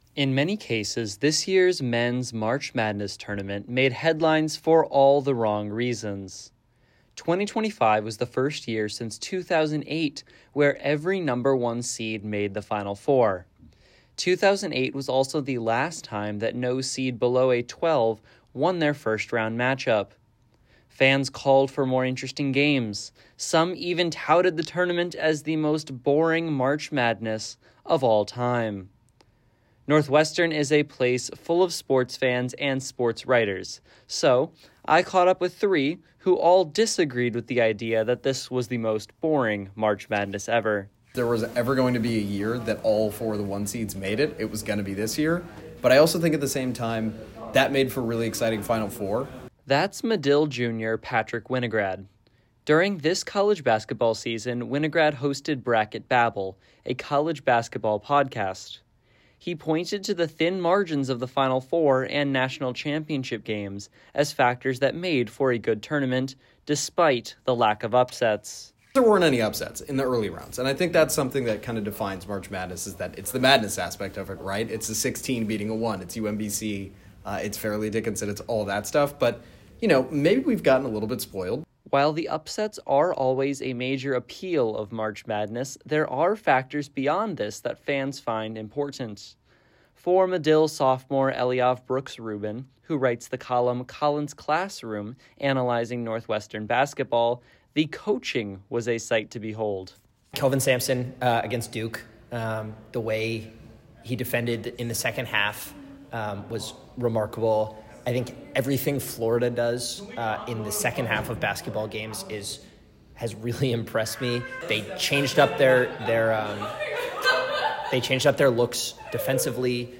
A basketball swipes through the net